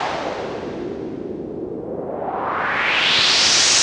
Index of /90_sSampleCDs/Classic_Chicago_House/FX Loops
cch_fx_wave_125.wav